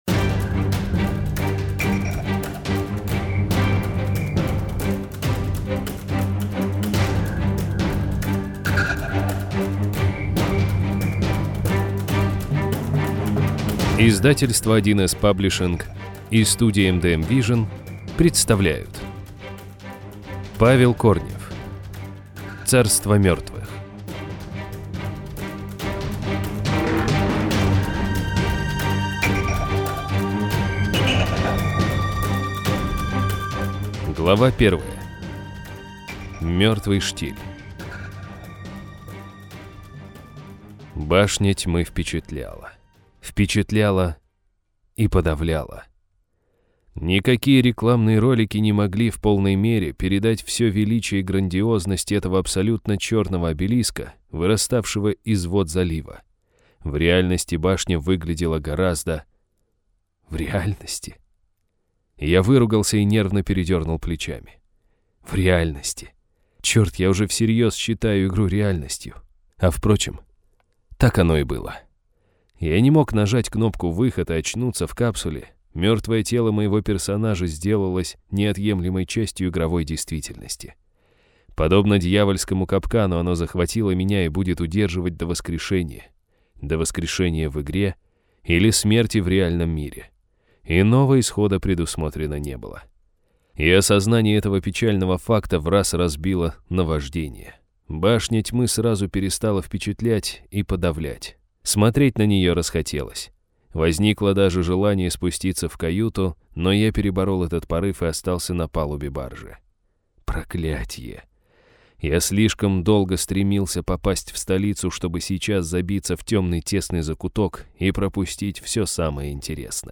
Аудиокнига Царство мертвых - купить, скачать и слушать онлайн | КнигоПоиск